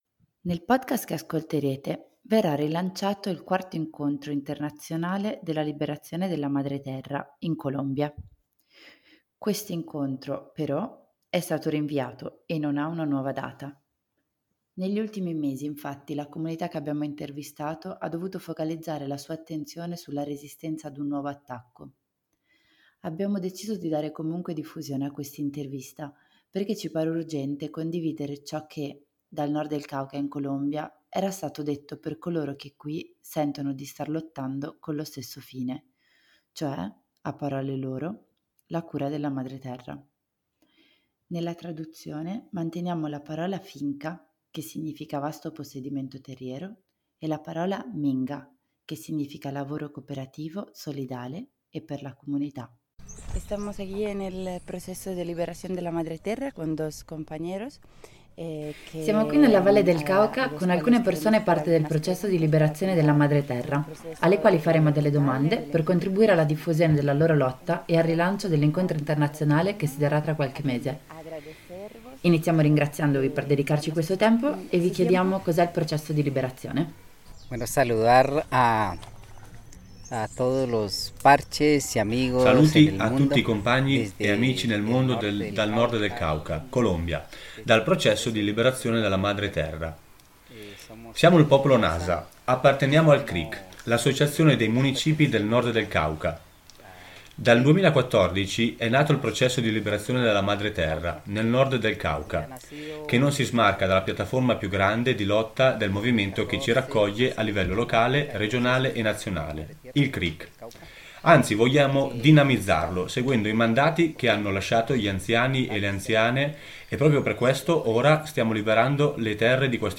Grazie alla presenza di due compagn* present* in quei luoghi, vi abbiamo ascoltare un’intervista a due rappresentanti del Processo, che ci hanno raccontato le idee, i metodi e le intenzioni di questo percorso di indipendenza e liberazione dalle nocività capitalistiche e statali, in una visione di mondo in cui la Terra è parte integrante della lotta stessa.